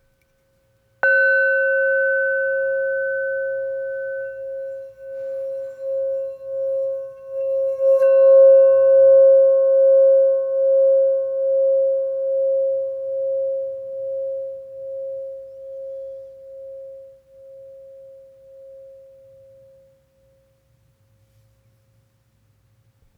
C Note 5.5″ Singing Bowl